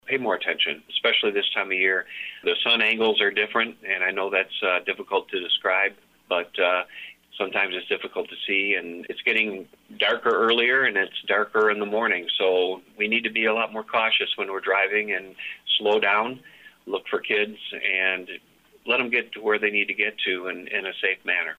Sheriff Bevier said sun angles can impact driving…
Troy-Bevier-Driving-Safety-2-9-1-21.mp3